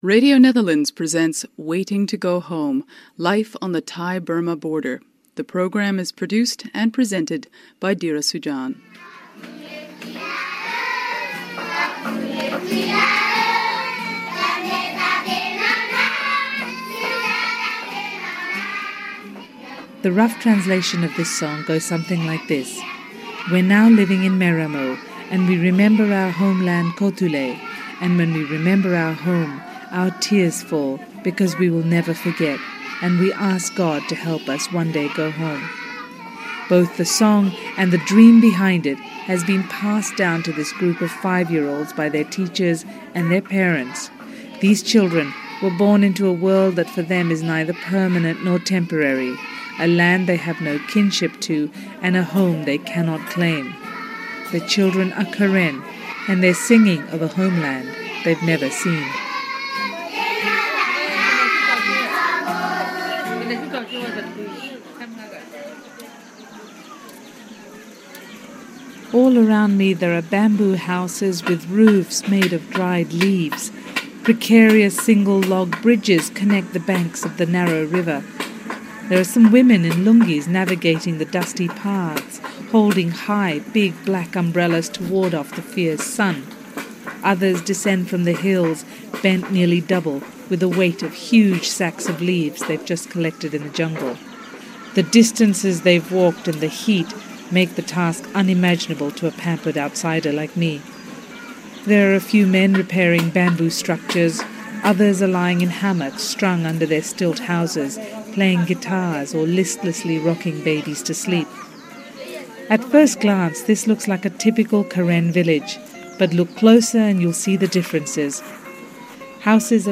They live a life that is neither temporary nor permanent, in harsh jungle conditions. I traveled to one such camp, Mae Ra Mo Loung, and found they are a collective example of the resilience of the human spirit.